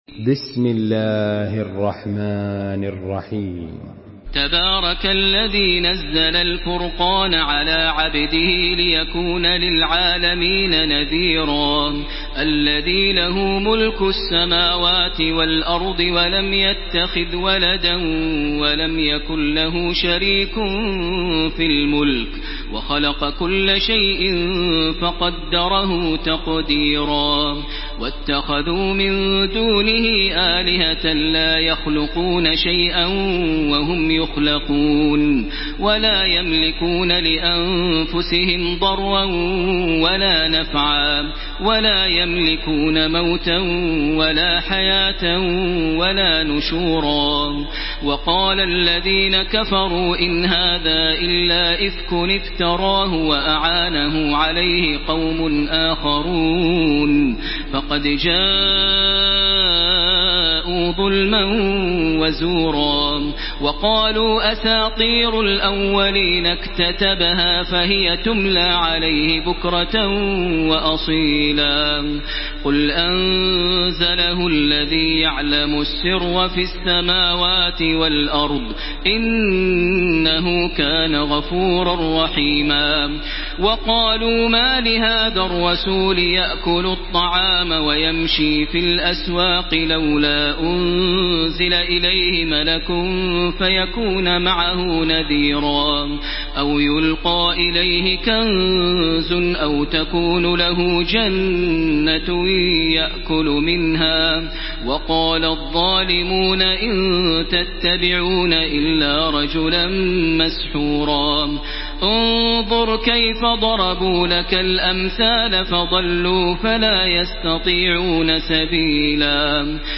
Surah আল-ফুরক্বান MP3 in the Voice of Makkah Taraweeh 1434 in Hafs Narration
Surah আল-ফুরক্বান MP3 by Makkah Taraweeh 1434 in Hafs An Asim narration.